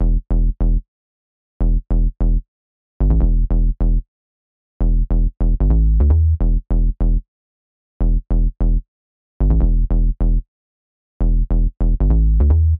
Tag: 150 bpm Ambient Loops Bass Loops 2.15 MB wav Key : Unknown